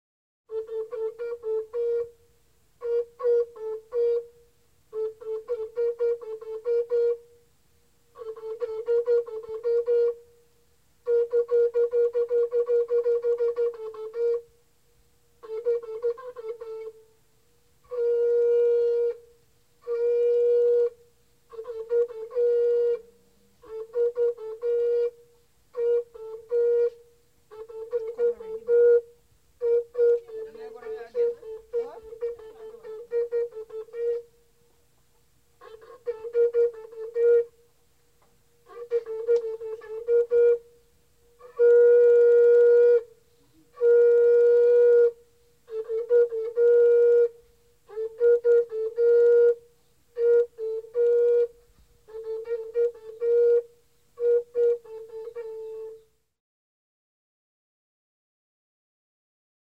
• Abeng: The abeng us an instrument made from the horn of a cow.  It is played by blowing through a side hole located near the tip; the thumb is simultaneously used to change pitch by covering another hole at the very tip.
Abeng: This recording was made during the Christmas holiday, the only time of the year that the abeng can be freely blown.  The player is here ushering in the holiday.
abeng.mp3